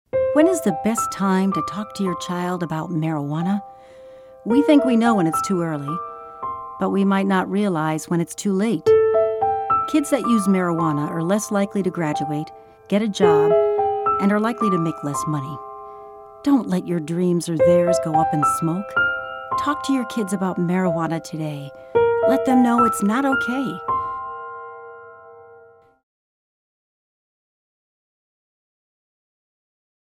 30-second radio spot